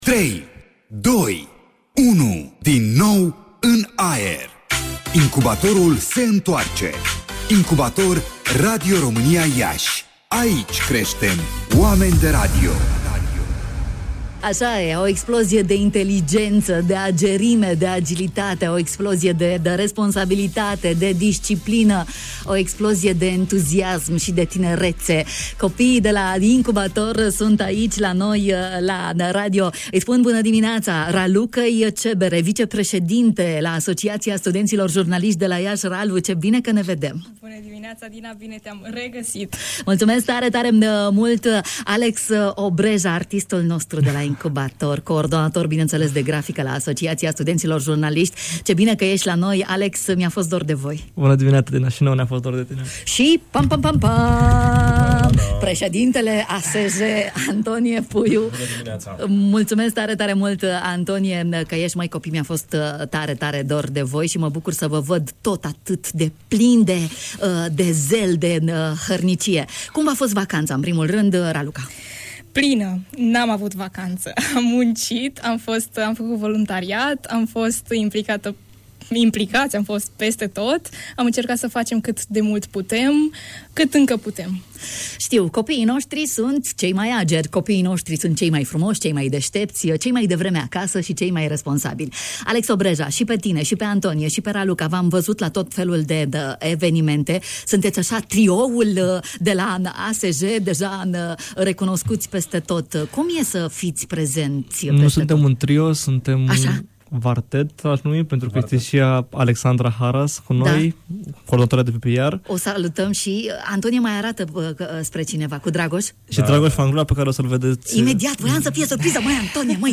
Voluntarii de la Asociația Studenților Jurnaliști s-au auzit astăzi în matinalul Radio România Iași.